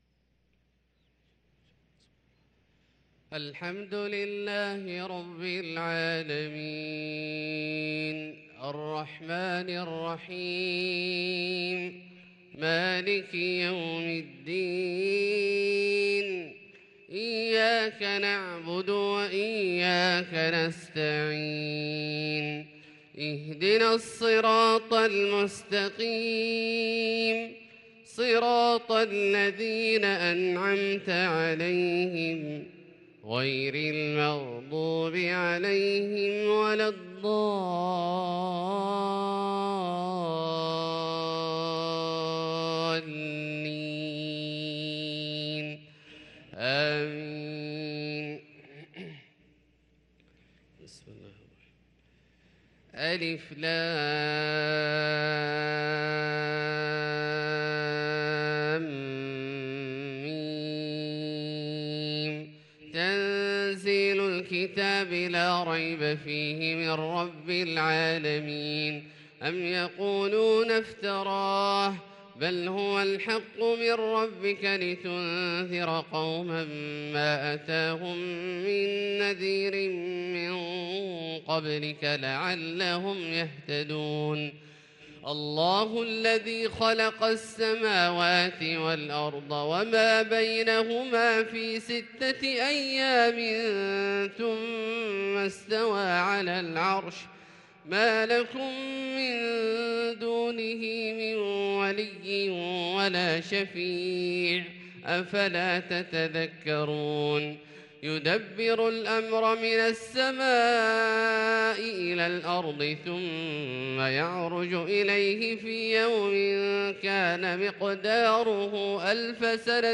صلاة الفجر للقارئ عبدالله الجهني 16 ذو الحجة 1443 هـ
تِلَاوَات الْحَرَمَيْن .